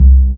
Fat Double Short (JW3).wav